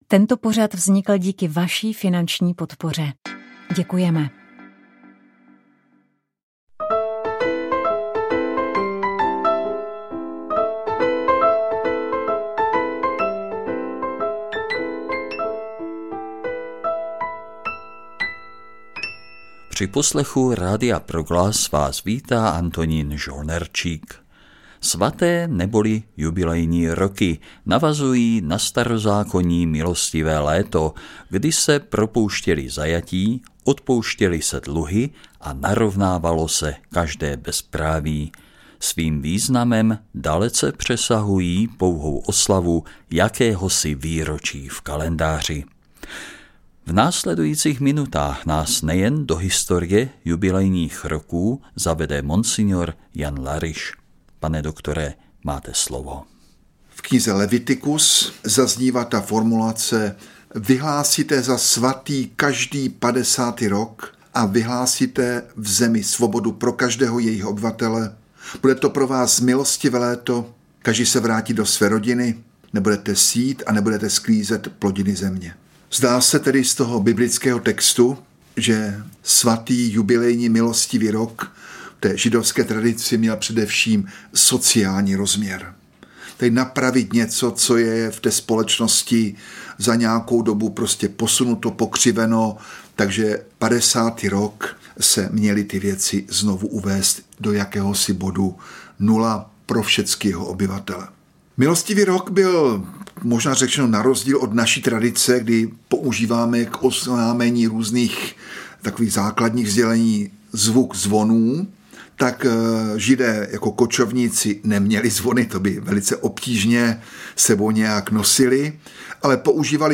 V cyklu Na stole je téma si poslechněte besedu o Katolické charismatické konferenci se třemi respondenty, kteří se na organizaci této velké akce podílejí.